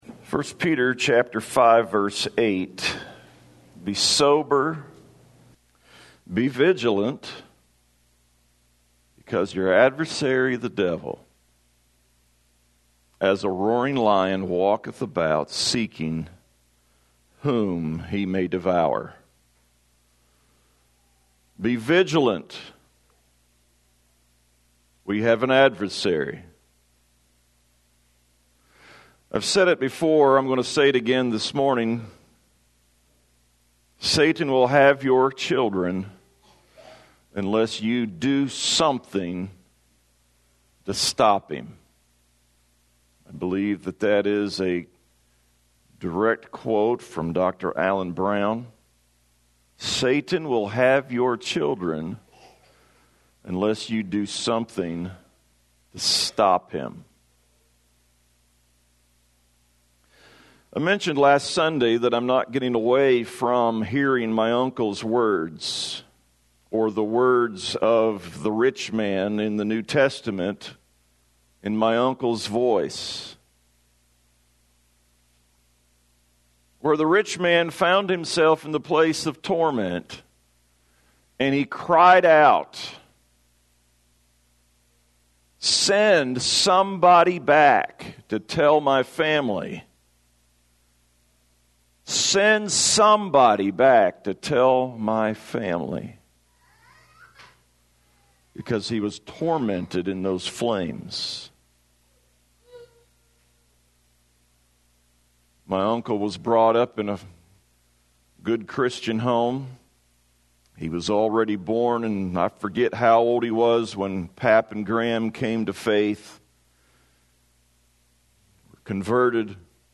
2020-8-9-am-sermon.mp3